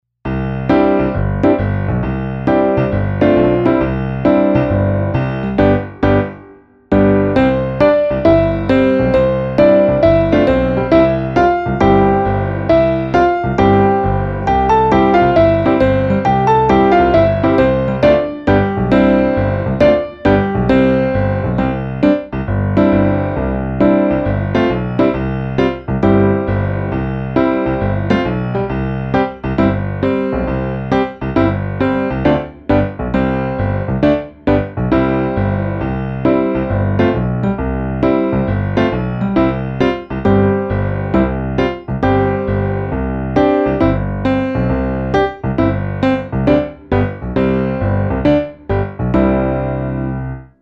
podkład muzyczny.
W nagraniu jest krótki wstęp, a potem 3 razy śpiewamy Panie Janie.